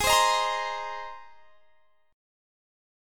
Listen to G#add9 strummed